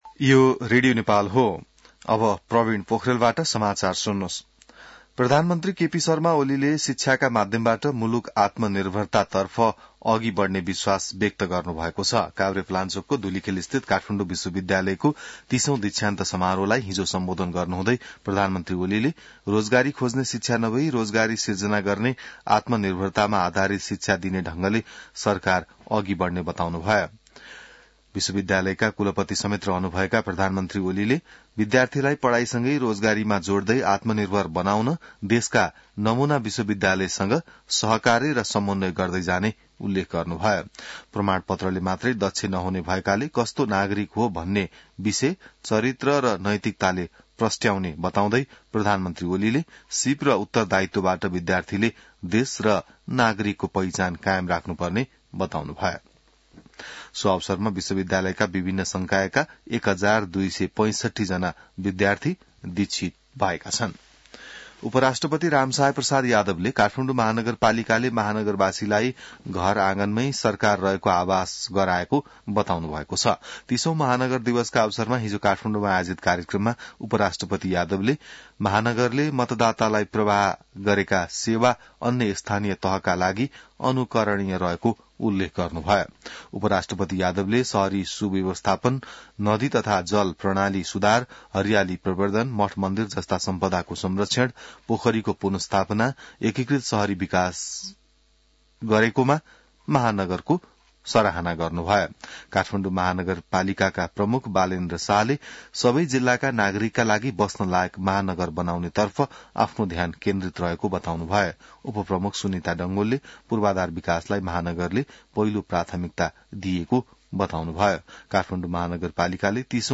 An online outlet of Nepal's national radio broadcaster
बिहान ६ बजेको नेपाली समाचार : १ पुष , २०८१